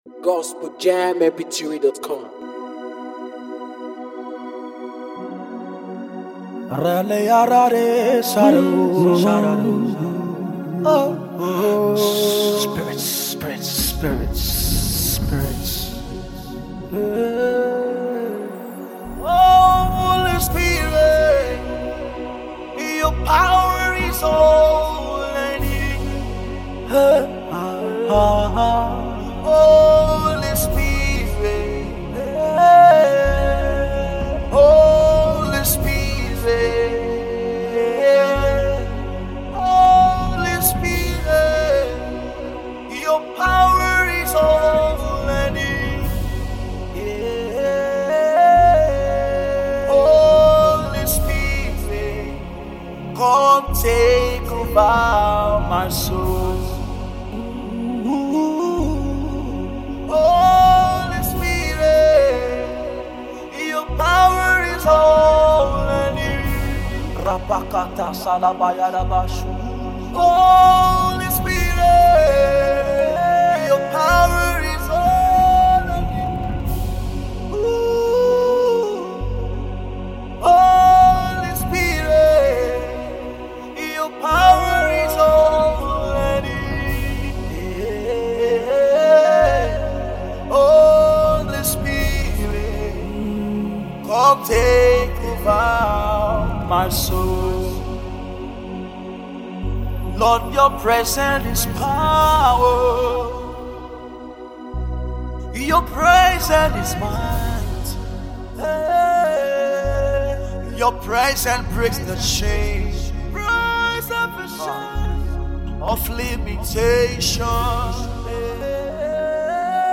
Nigeria gospel singer